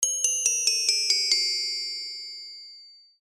叮当
描述：很适合80年代和圣诞歌曲